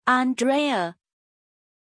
Pronuncia di Andreea
pronunciation-andreea-zh.mp3